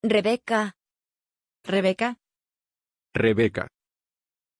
Pronunția numelui Rebeka
pronunciation-rebeka-es.mp3